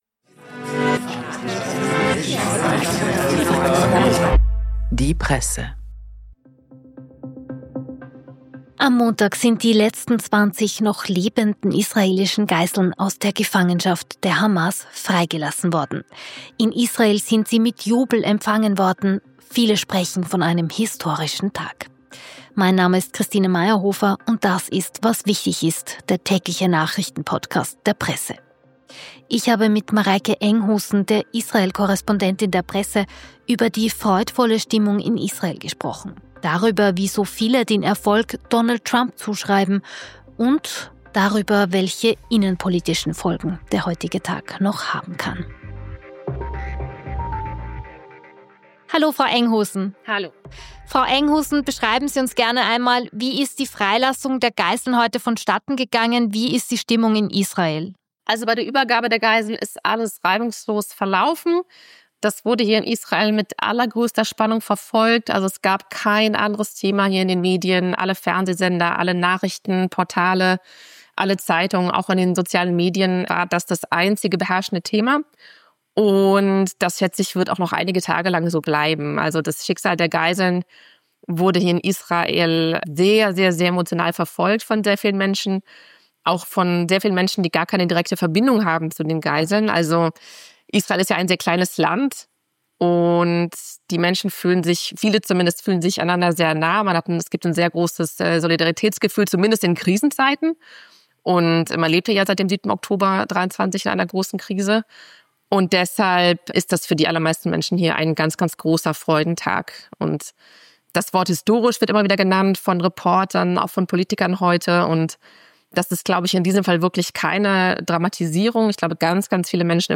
Bericht aus Tel Aviv